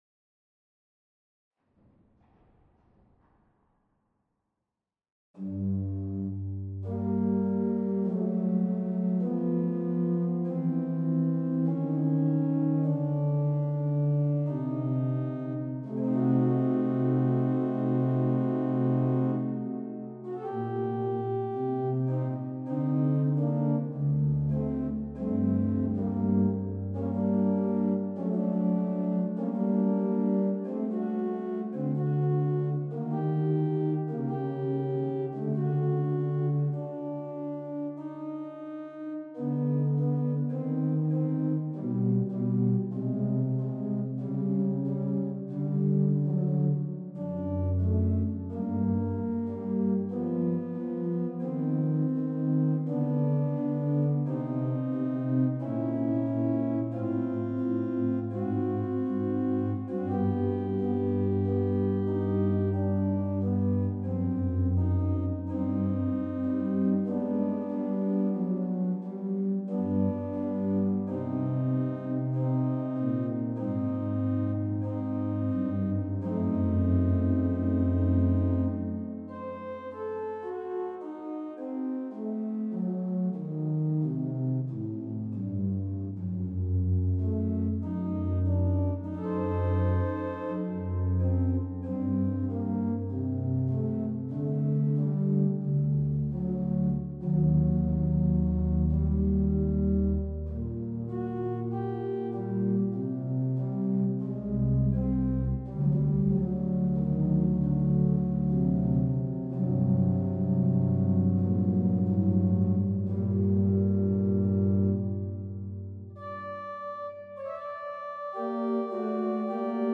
Kehl’s claim to fame are the chorale preludes he published during his time in Erlangen, in four different volumes (Erste bis Vierte Sammlung einiger variierender Choräle, 1764).
Kehl’s preludes are relatively simple, with no or a modest pedal part.